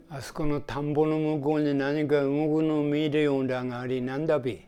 Aizu Dialect Database
Type: Single wh-question
Final intonation: Falling
WhP Intonation: Peak
Location: Showamura/昭和村
Sex: Male